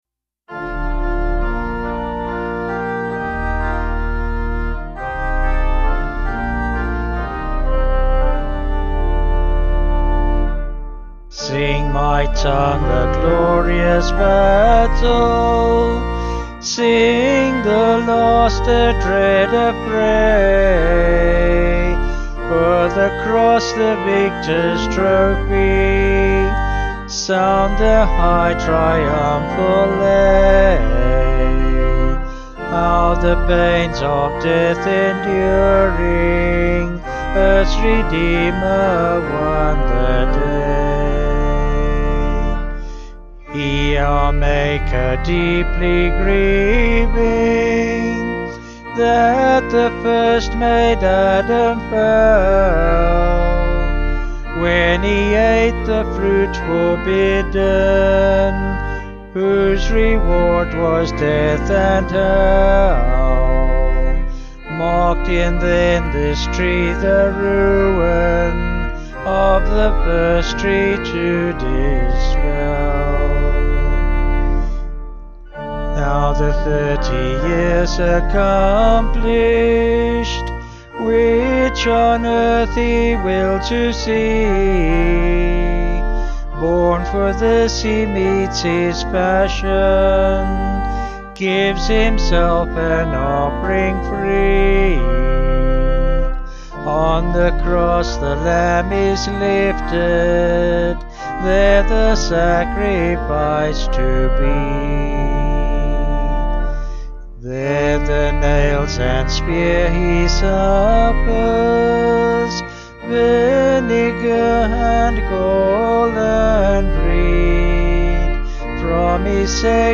Vocals and Organ   285.3kb Sung Lyrics